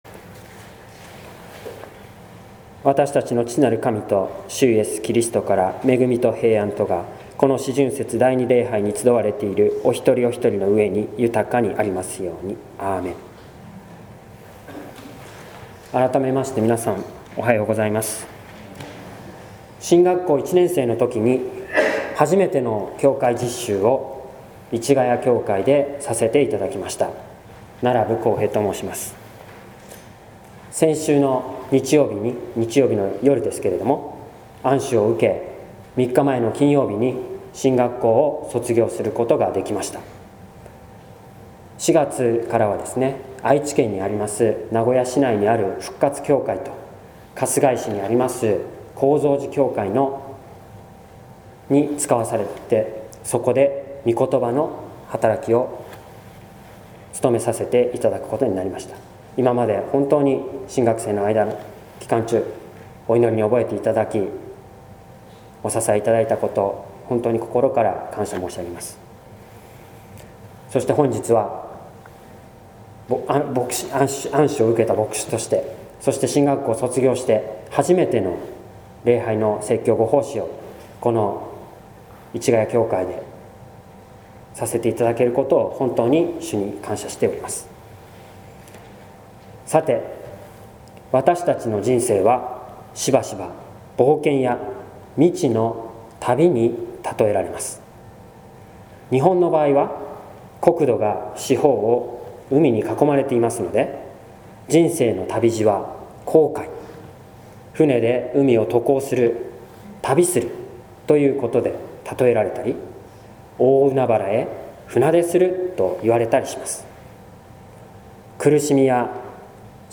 説教「信仰者のあり方」（音声版）